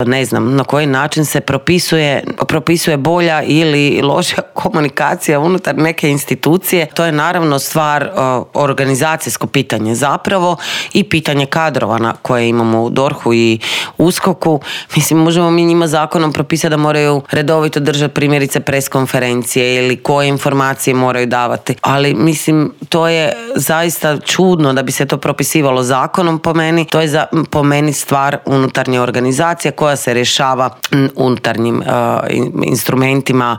ZAGREB - U Intervjuu tjedna Media servisa gostovala je saborska zastupnica i premijerska kandidatkinja stranke Možemo Sandra Benčić, koja je prokomentirala nove izmjene koje je Vlada najavila uvrstiti u konačni prijedlog tzv. Lex AP-a, osvrnula se na reakciju vladajućih na jučerašnji prosvjed HND-a zbog kaznenog djela o curenju informacija, ali i na kandidata za glavnog državnog odvjetnika Ivana Turudića.